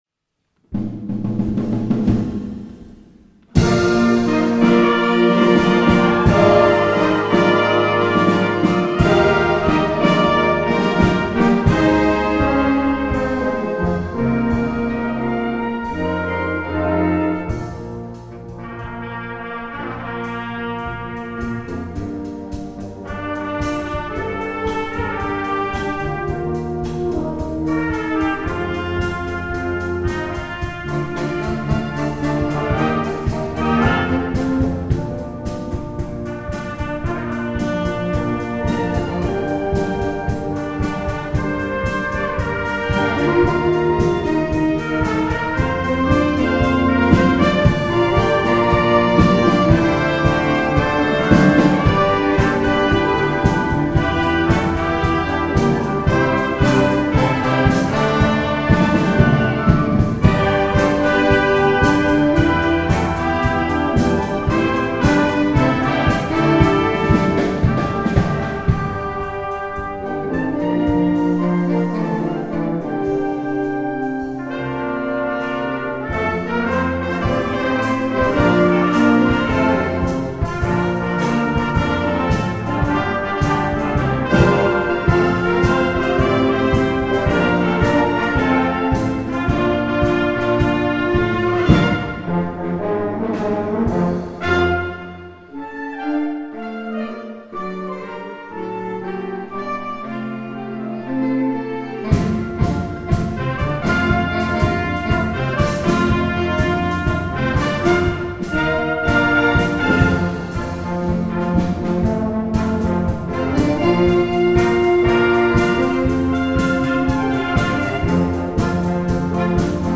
Konzert 2014